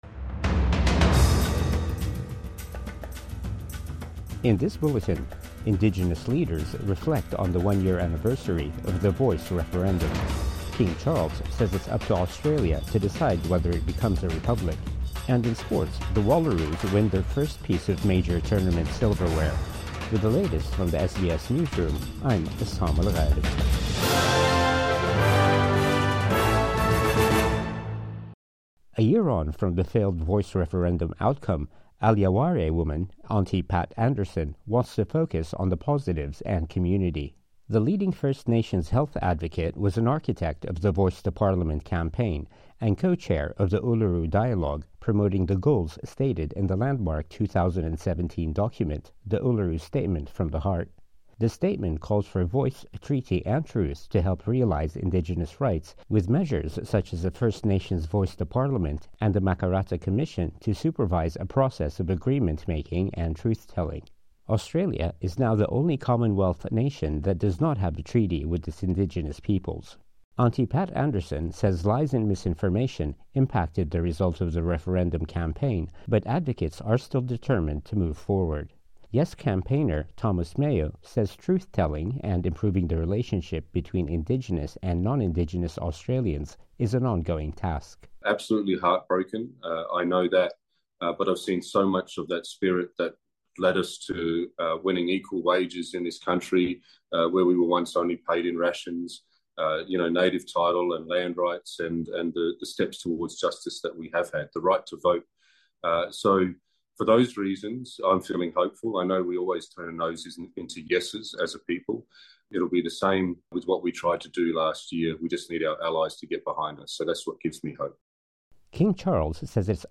Midday News Bulletin 13 October 2024